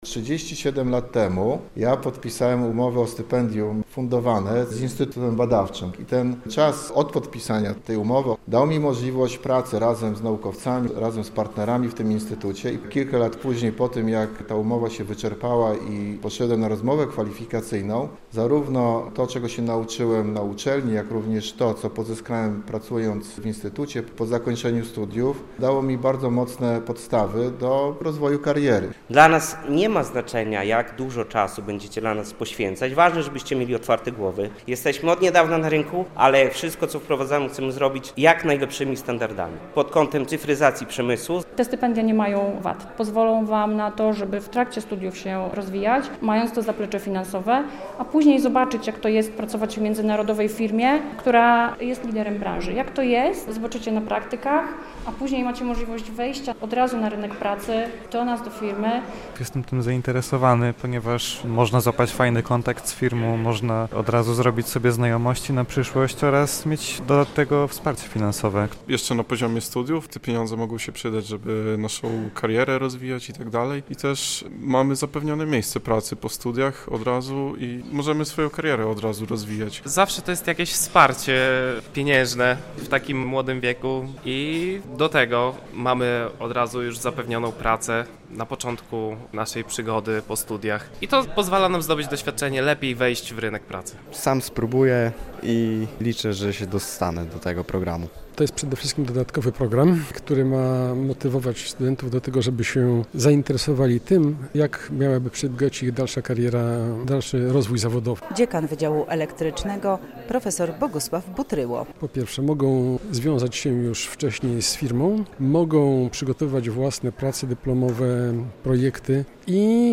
Dzień Fundatorów na Wydziale Elektrycznym Politechniki Białostockiej - relacja